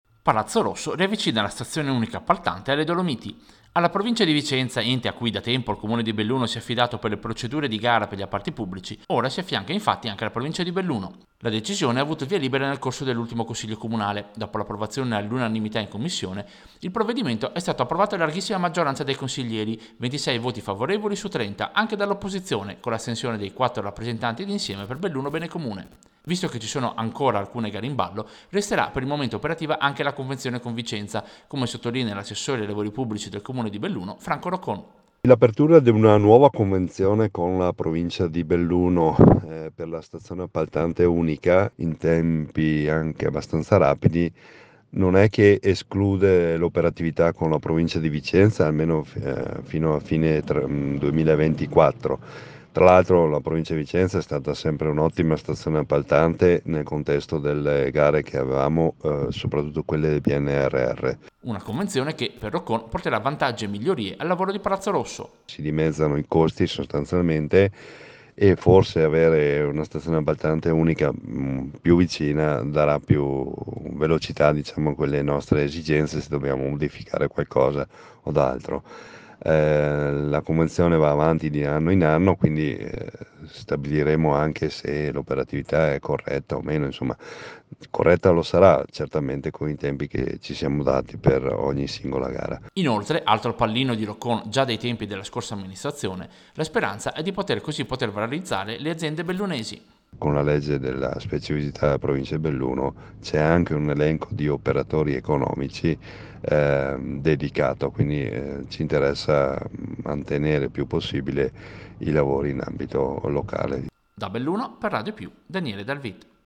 Servizio-Stazione-appaltante-capoluogo-a-Belluno.mp3